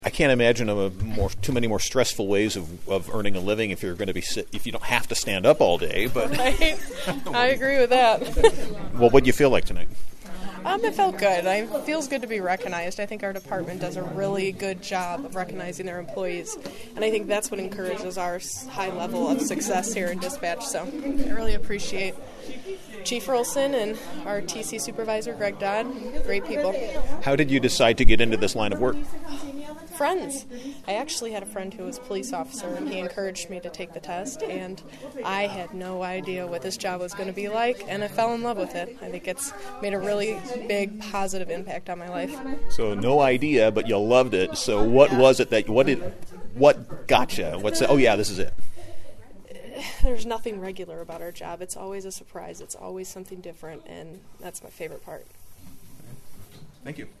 The interview